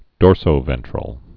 (dôrsō-vĕntrəl)